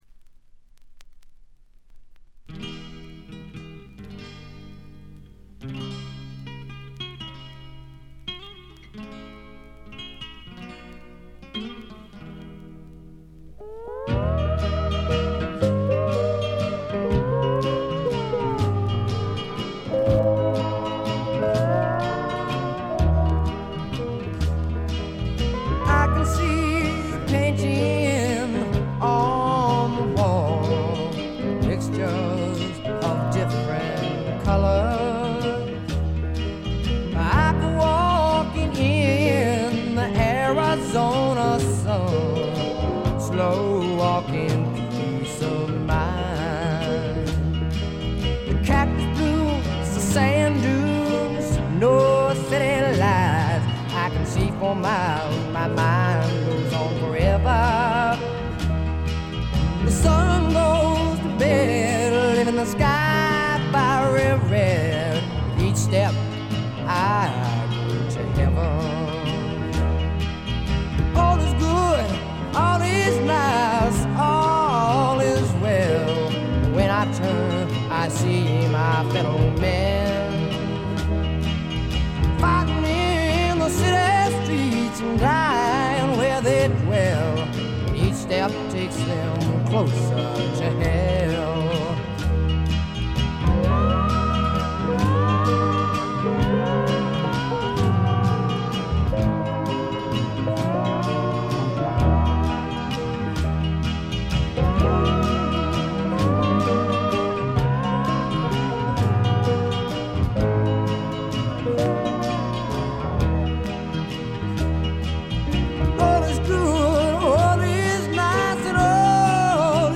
軽度のバックグラウンドノイズ、ところどころでチリプチ。
最小限の編成が織り成す、ねばつくような蒸し暑いサウンド。
試聴曲は現品からの取り込み音源です。
organ, piano, harmonica, background vocals
drums, percussion, background vocals